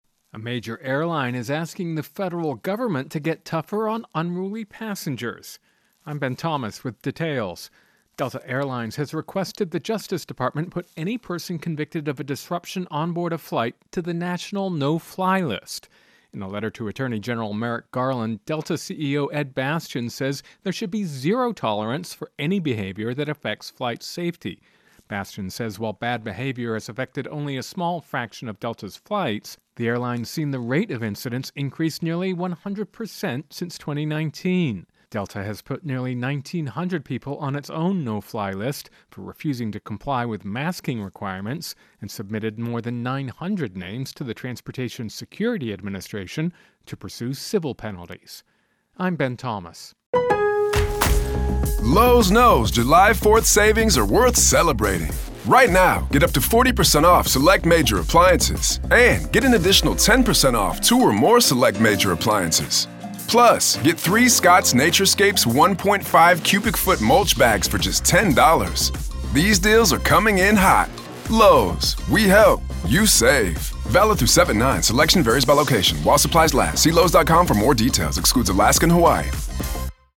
Intro and voicer "Delta No Fly List"